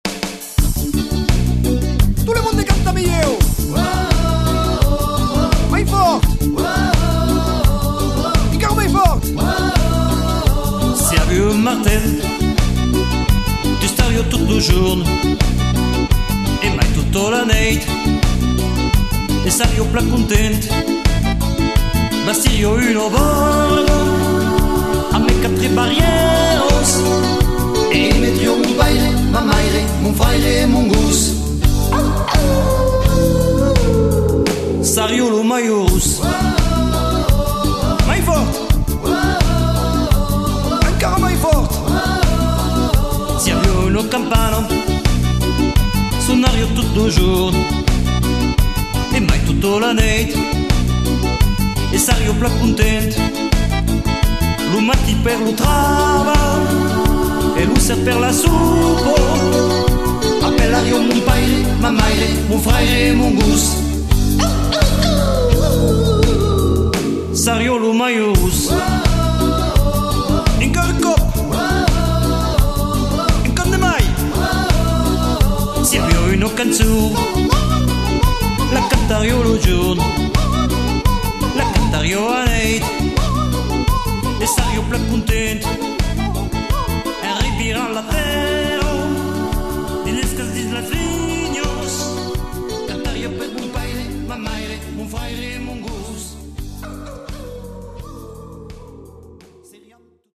rock délire en occitan !!